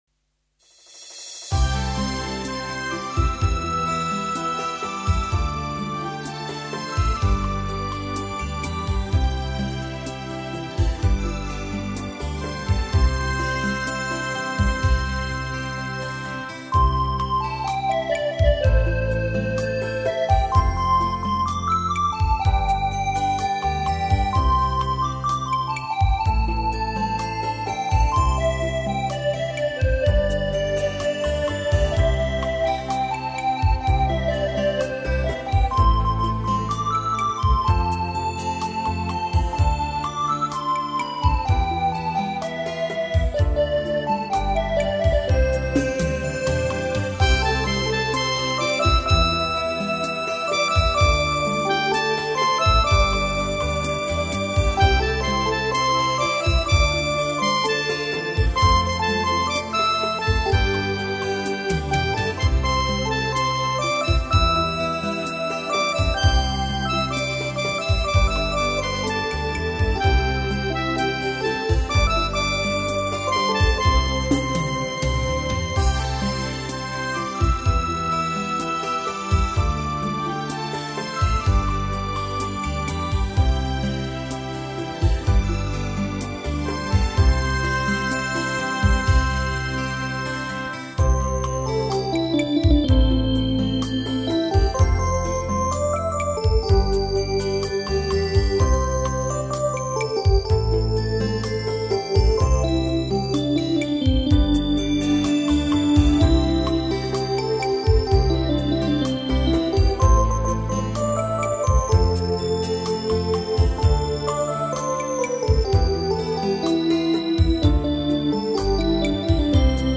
由于这些乐曲较合理地开发并运用了电子琴的多种演奏功能与技巧，而展现了电子琴音乐新颖独特的艺术魅力，令听众耳目一新。